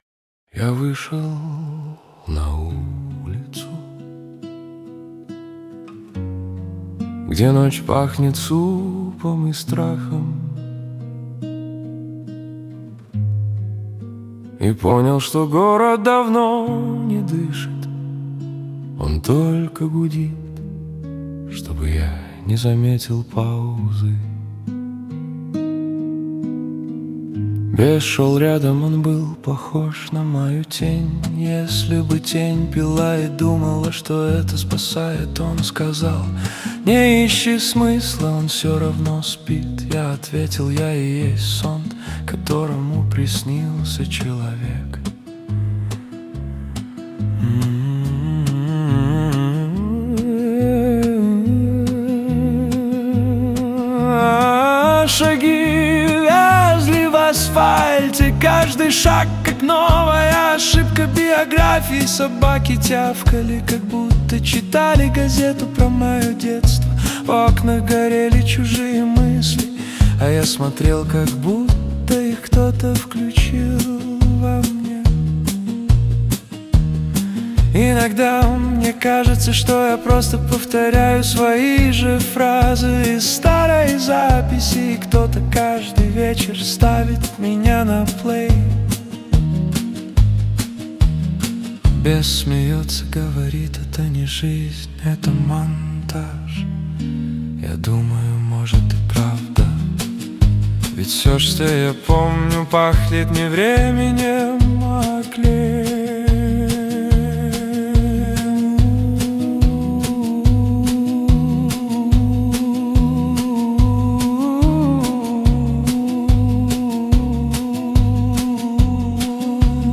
• Исполняет: Поставторcкий арт
[Навязчивость - короткий повтор мотива. Фон усиливается, голос звучит ближе, с ревербом.]
[Финал - всё постепенно растворяется: шум плёнки, слабый аккорд рояля, одинокая нота держится и гаснет.]